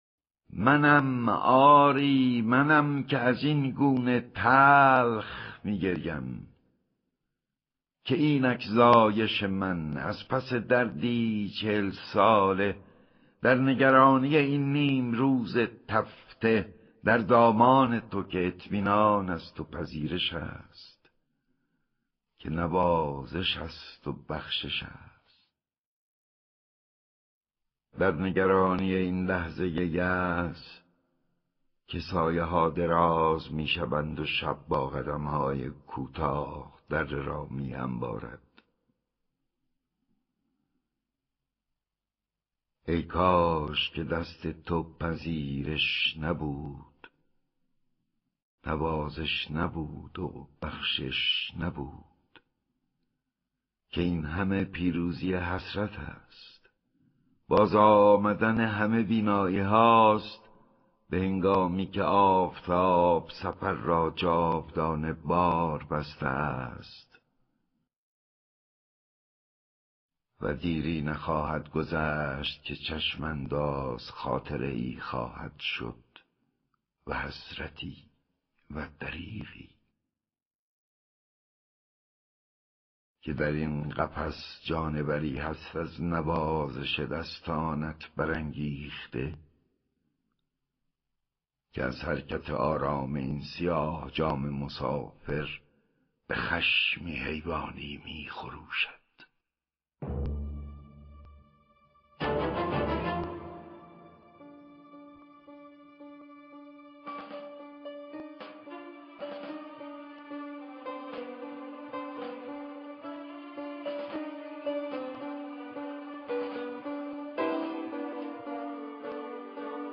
دانلود دکلمه منم آری منم با صدای احمد شاملو
گوینده :   [احمد شاملو]